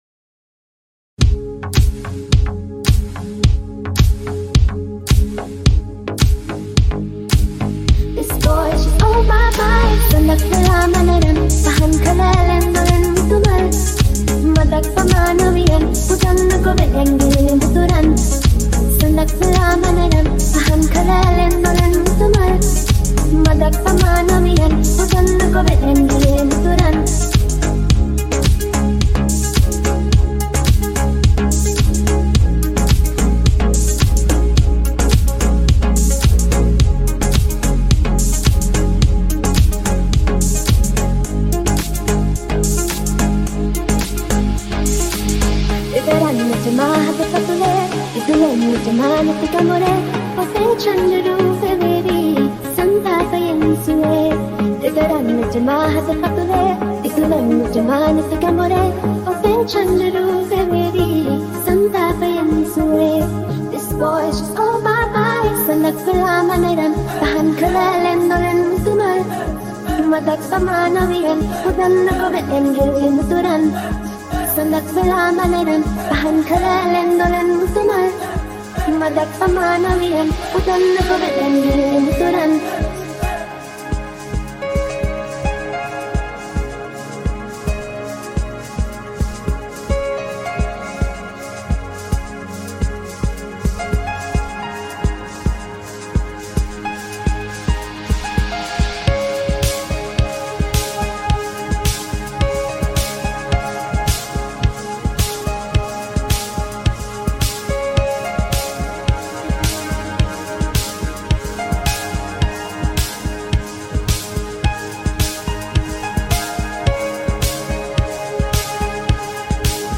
Progressive House Remake